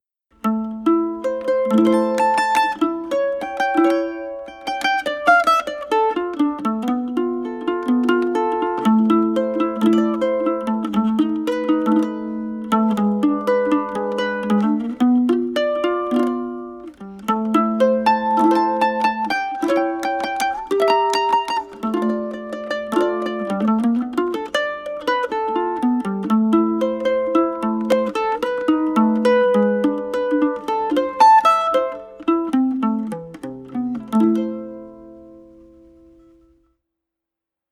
Bandolim 10 cordes Regis Bonilha
Je l’ ai montée avec des filets plats, le son est plus moelleux……
bandolim.mp3